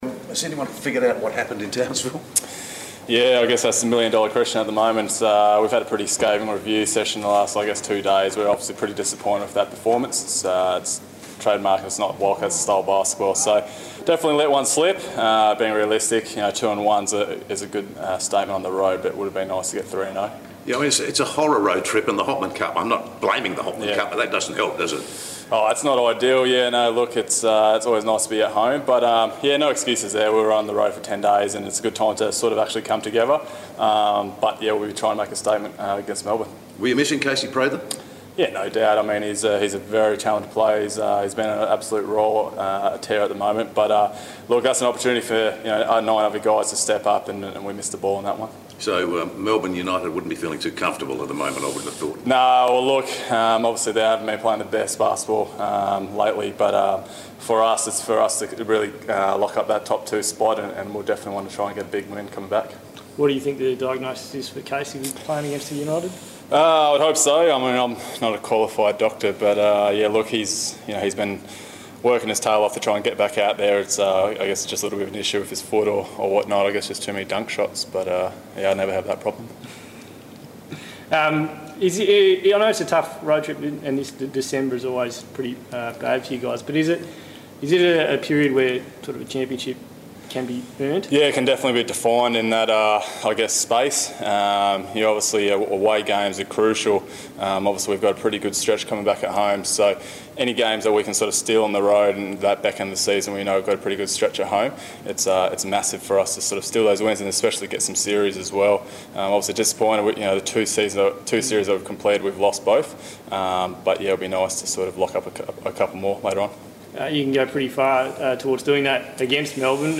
Press Conference